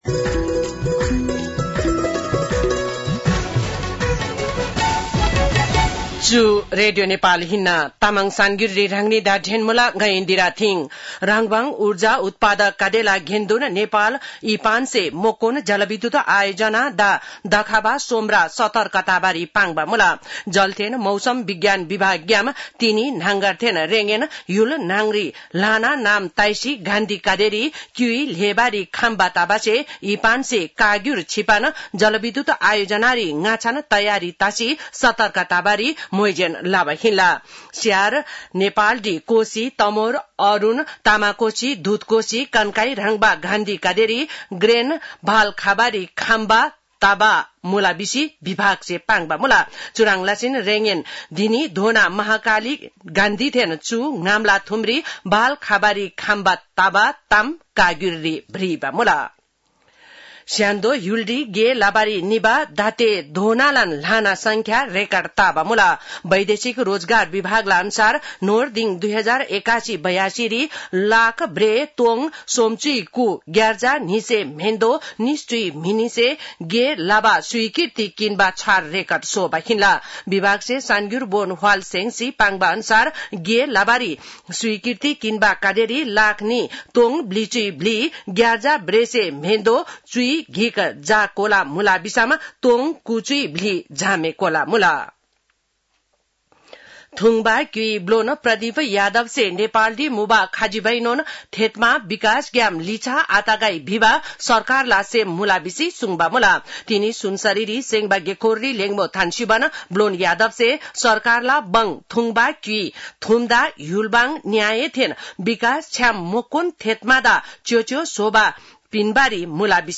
तामाङ भाषाको समाचार : ३ साउन , २०८२